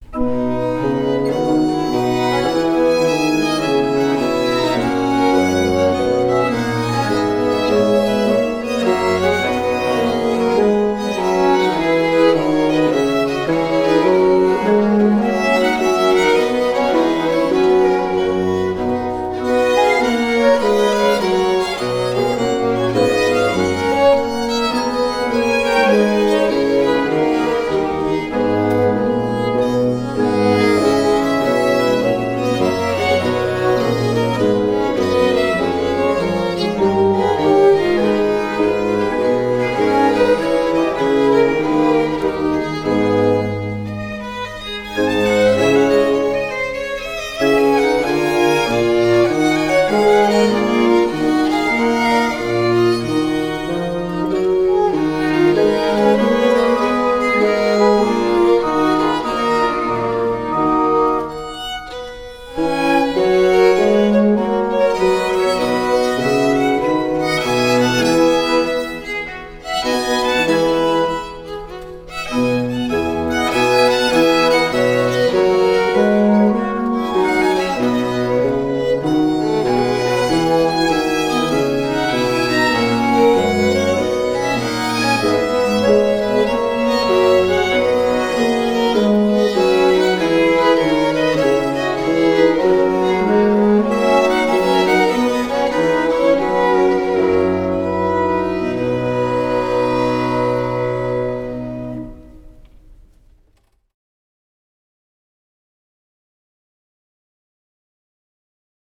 Sinfonia
8-Bach_BWV_196_Sinfonia.mp3